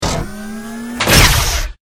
battlesuit_smalllaser.ogg